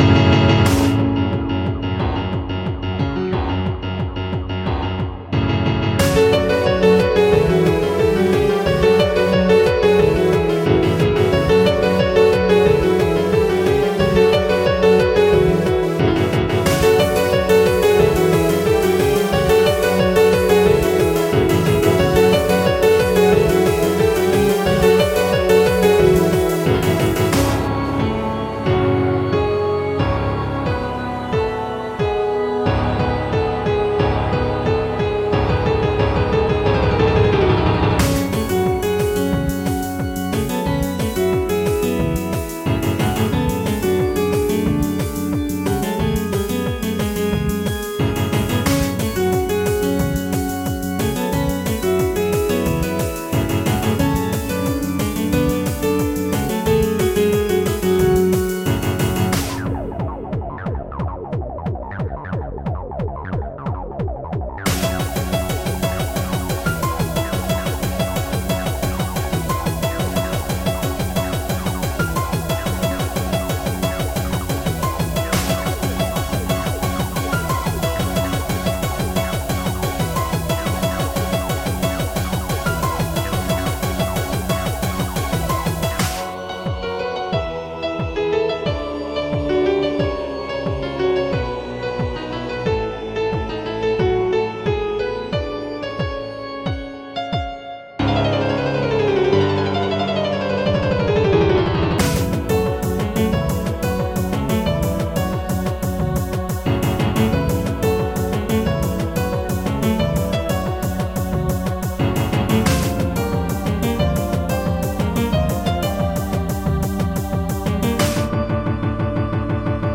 BPM90-360
MP3 QualityMusic Cut